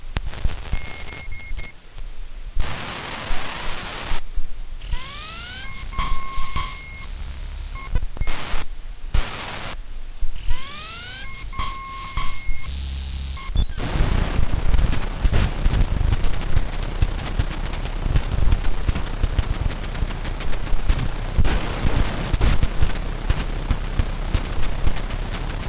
Mac Book Pro CD驱动器工作
描述：Mac Book Pro的CD驱动器接收、读取和弹出CD.
Tag: 嗡嗡声 CD 计算机 驱动器 膝上型计算机 的MacBook 电机 工作